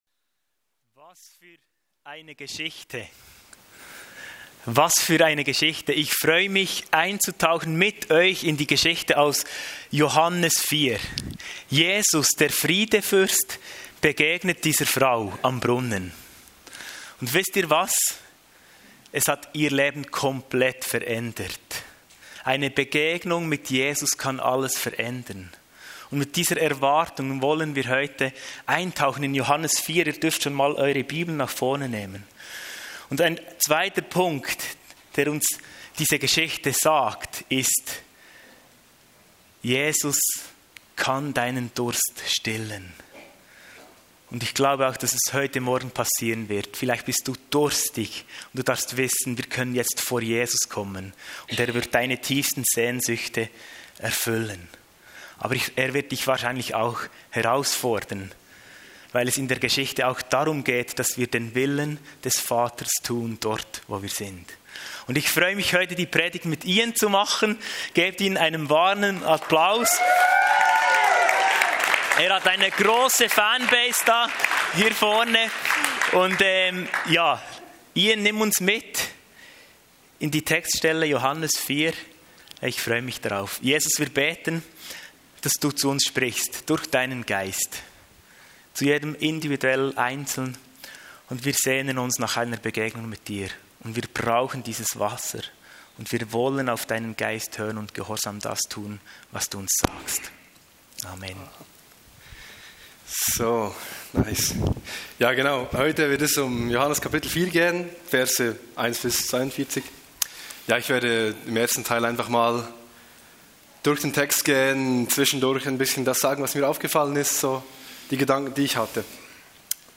Eine Auswahl an Predigten der FMG Frutigen (Schweiz).